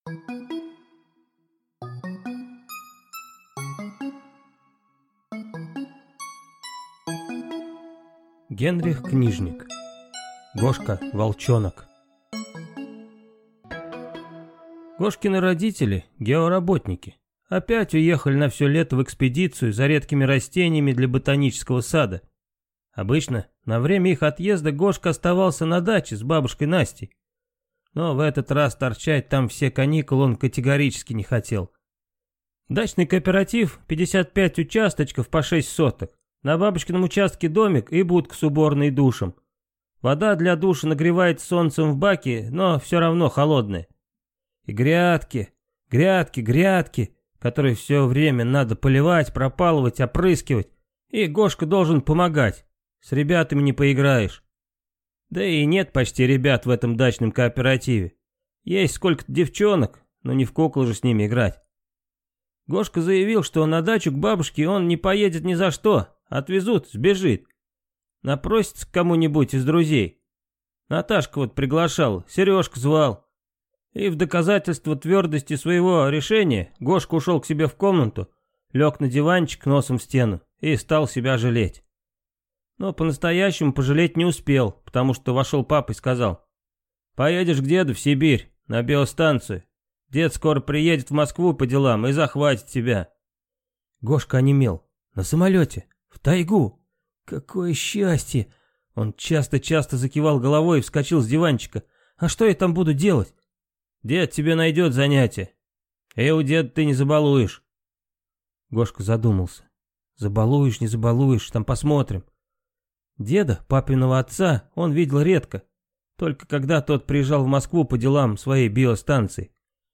Аудиокнига Гошка-волчонок | Библиотека аудиокниг